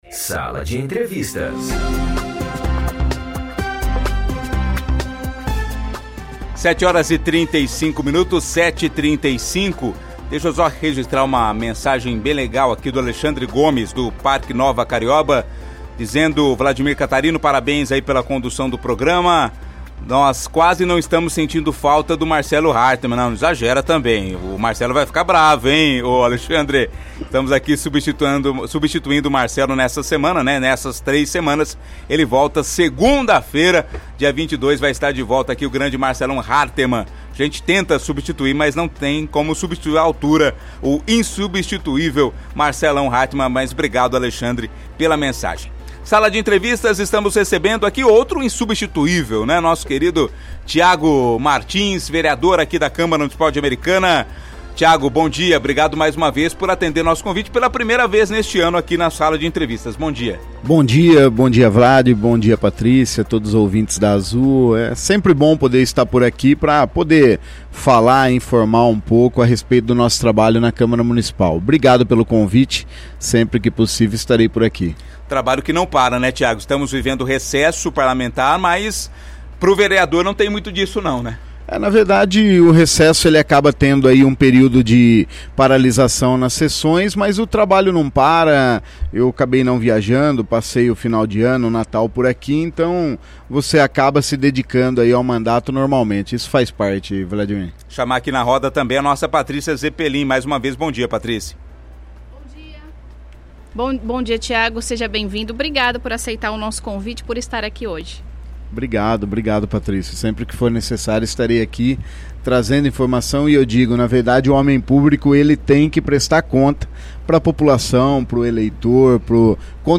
Entrevista com Thiago Martins, vereador em Americana
Thiago Martins, vereador em Americana, concedeu entrevista exclusiva ao Sala de Entrevistas, do Azul Cidades na manhã desta segunda-feira, 15 de janeiro de 2024.
entrevista-tiago-martins.mp3